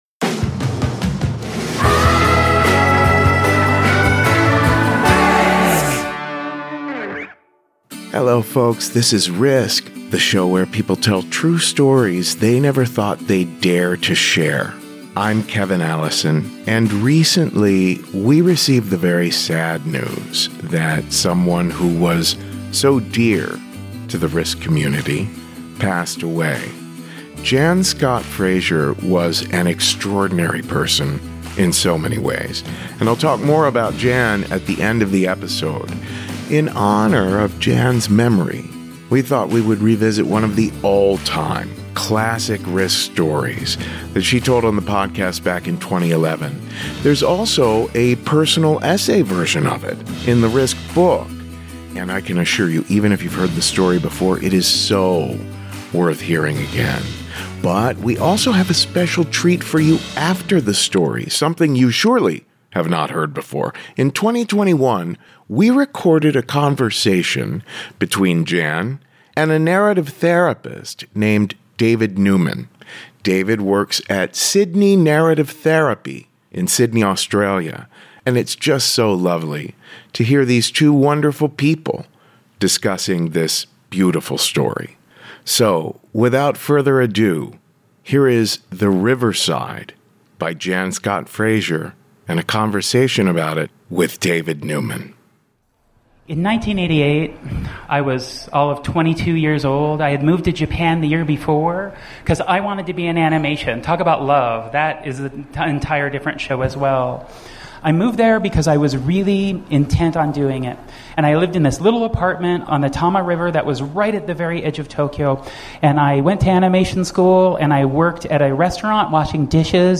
🎤Live Story